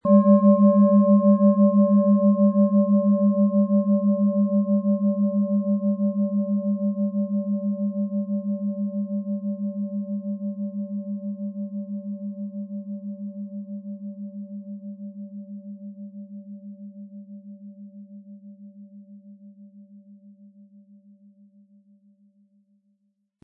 Tibetische Universal-Klangschale, Ø 15,8 cm, 500-600 Gramm, mit Klöppel
HerstellungIn Handarbeit getrieben
MaterialBronze